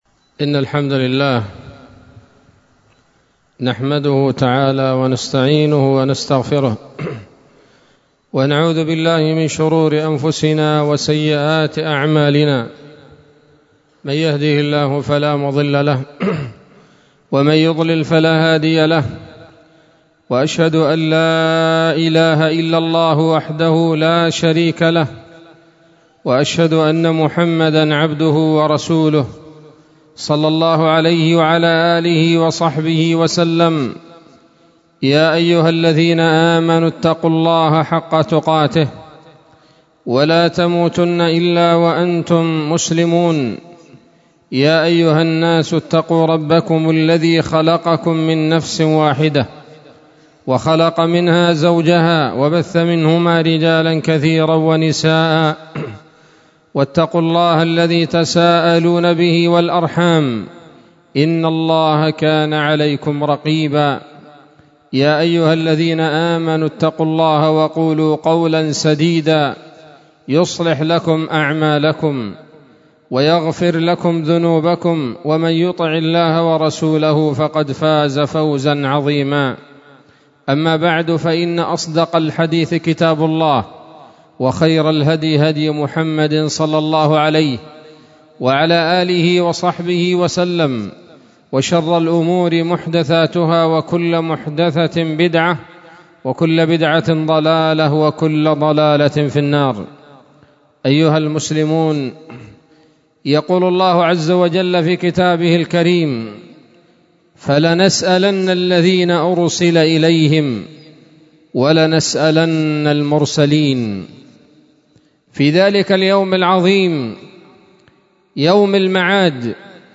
خطبة جمعة بعنوان: (( سؤال أهل النار يوم القيامة )) 10 ربيع الآخر 1444 هـ، دار الحديث السلفية بصلاح الدين
سؤال-أهل-النار-يوم-القيامة-خطبة.mp3